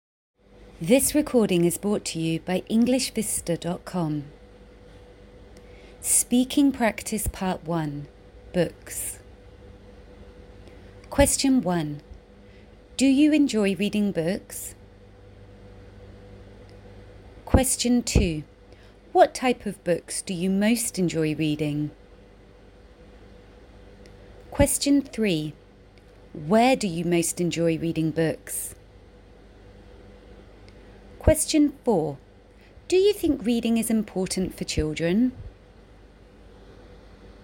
Play the recording and pause it after each question.